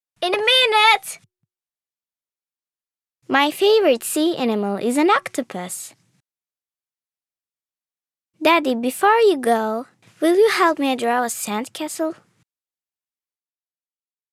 Weiblich | Kind